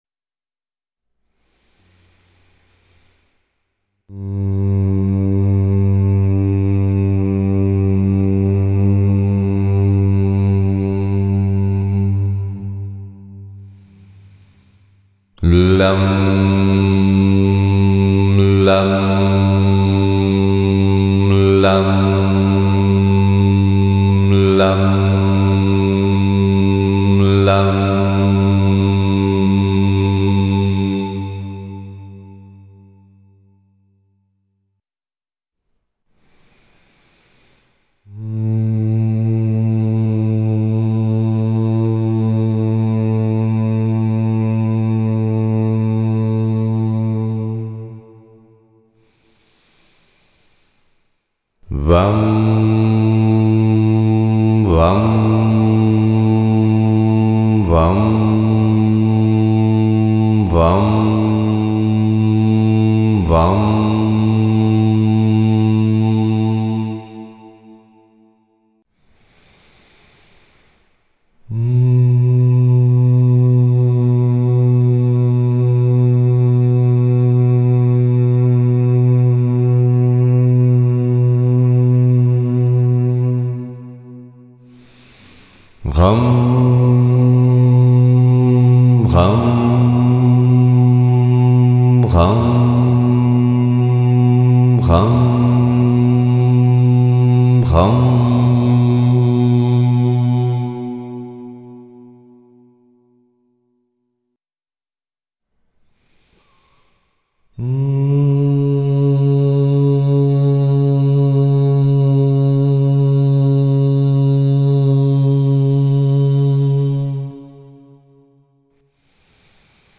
Bija-chakra-Mantry.mp3